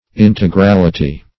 Integrality \In`te*gral"i*ty\, n. [Cf. F. int['e]gralit['e].]